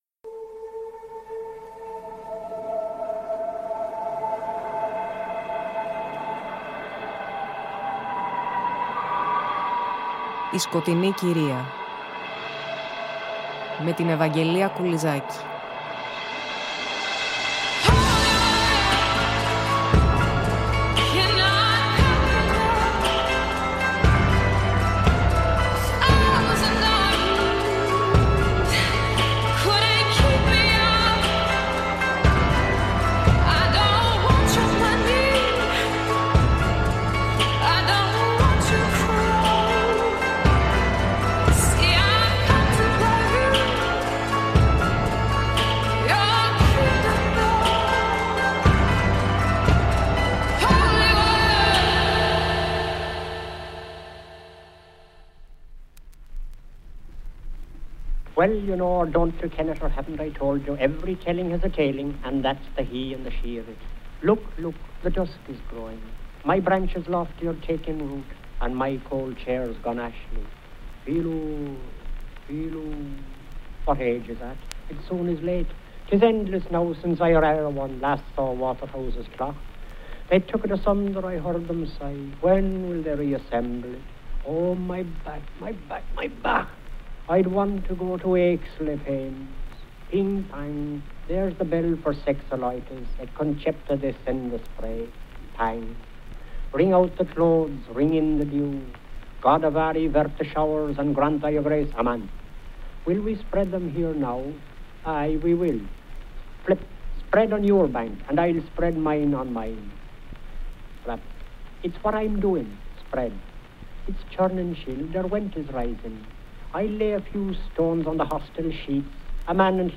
Ηχητικό bonus της εκπομπής η φωνή του ίδιου του Joyce , σε ανάγνωση αποσπασμάτων από το “Finnegan’ s Wake” .